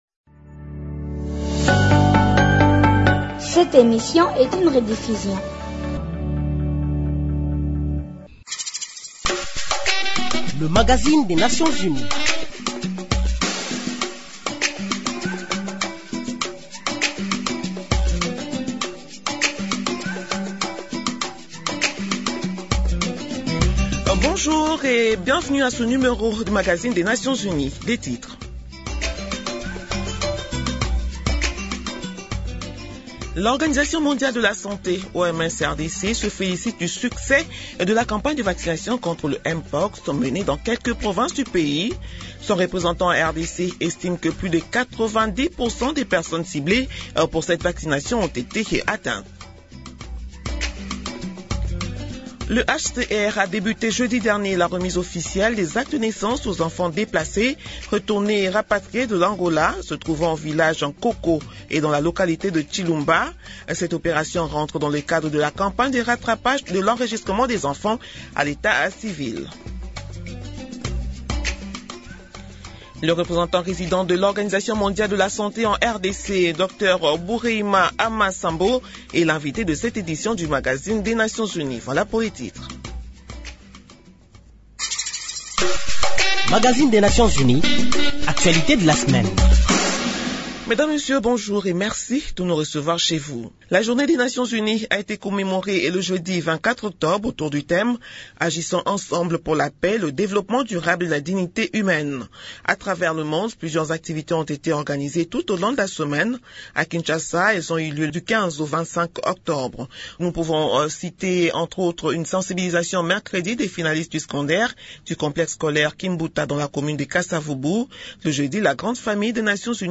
Nouvelles en bref Au Nord-Kivu, le Programme des Nations Unies pour le Développement (PNUD) vient de doter le commissariat provincial de la police d’un Centre de coordination des opérations (CCO). Il s’agit d’un bâtiment, nouvellement construit et équipé du matériel nécessaire pour le suivi, sur le terrain, de toutes les actions engagées pour assurer la sécurité de la population et de ses biens et endiguer la criminalité.